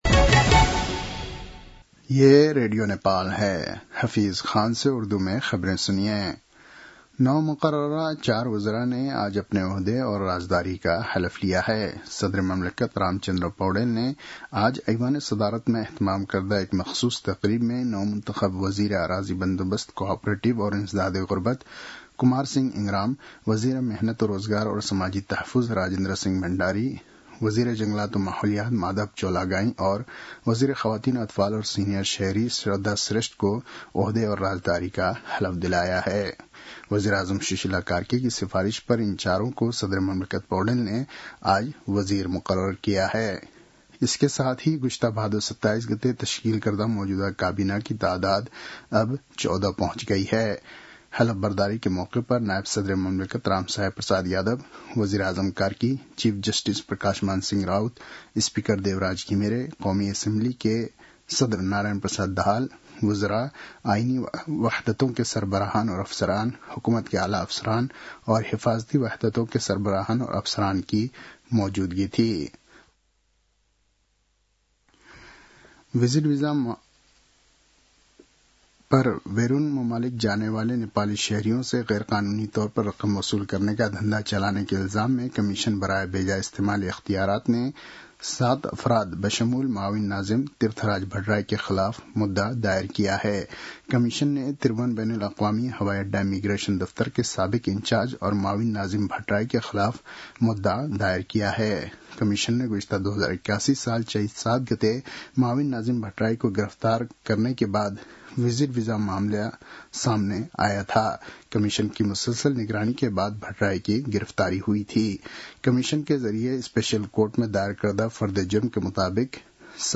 उर्दु भाषामा समाचार : २६ मंसिर , २०८२
Urdu-news-8-26.mp3